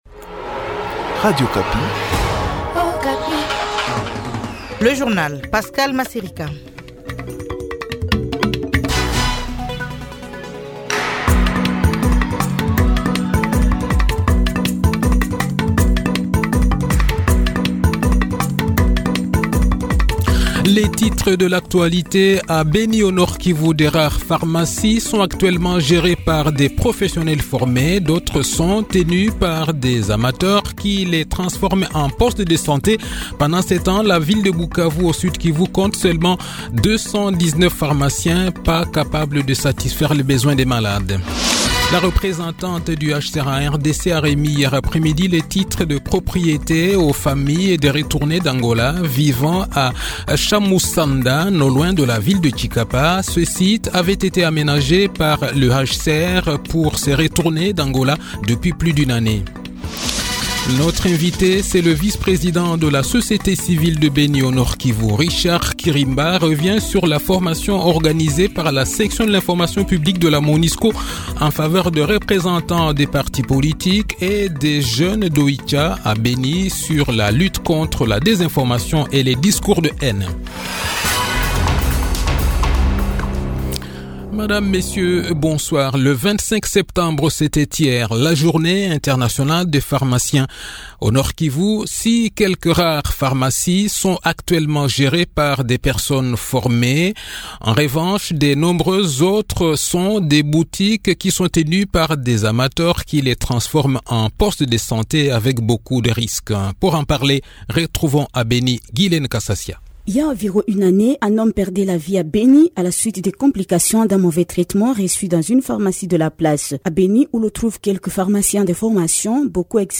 Le journal de 18 h, 26 septembre 2023